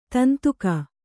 ♪ tantuka